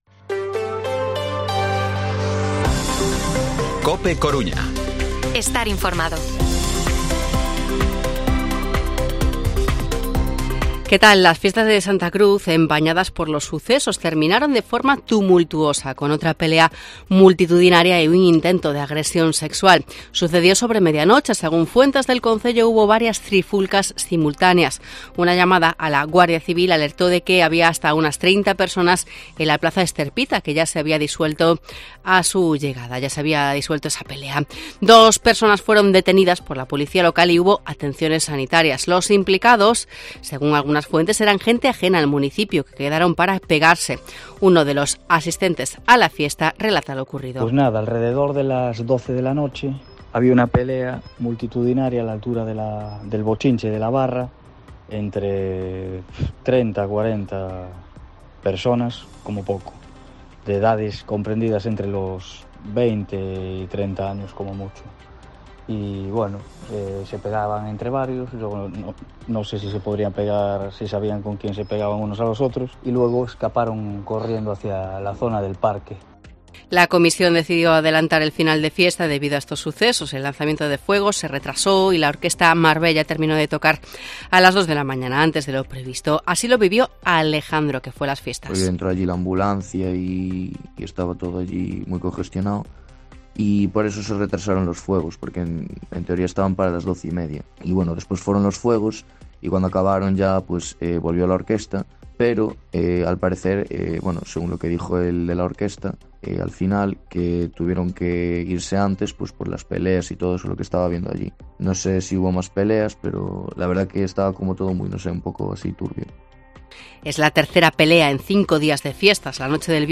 Informativo Mediodía COPE Coruña miércoles, 23 de agosto de 2023 14:20-14:30